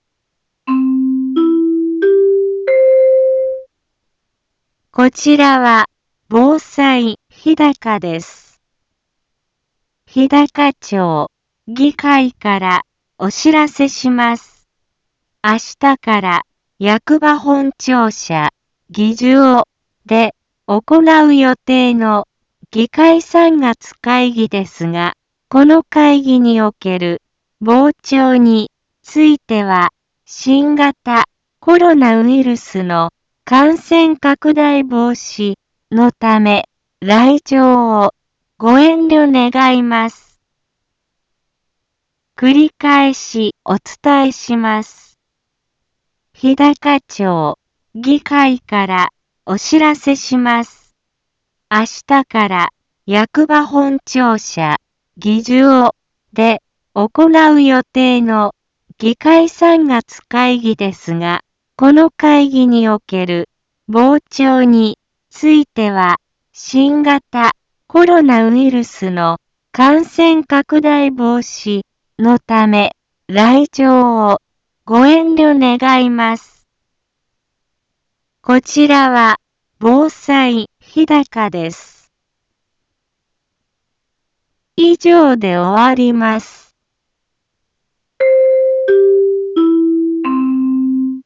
一般放送情報
BO-SAI navi Back Home 一般放送情報 音声放送 再生 一般放送情報 登録日時：2020-03-04 15:03:14 タイトル：日高町３月会議のお知らせ インフォメーション： こちらは、防災日高です。